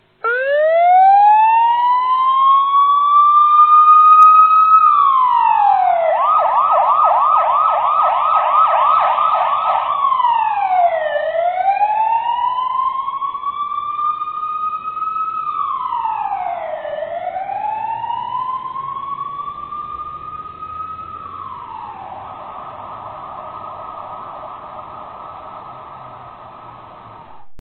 دانلود صدای ماشین پلیس 1 از ساعد نیوز با لینک مستقیم و کیفیت بالا
جلوه های صوتی